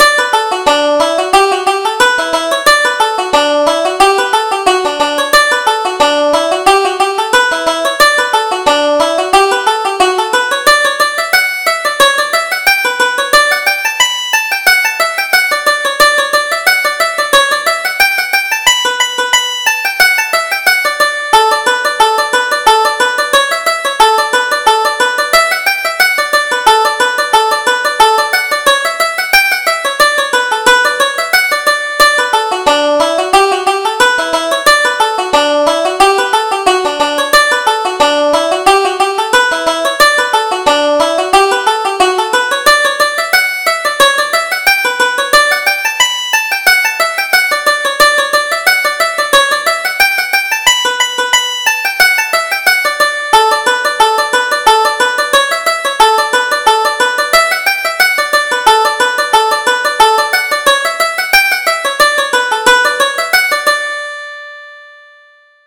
Reel: The Limerick Lasses